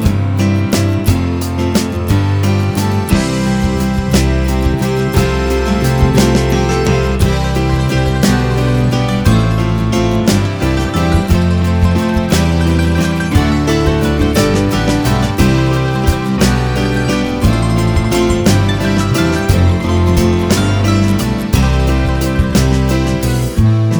Minus Harmonica Pop (1980s) 5:38 Buy £1.50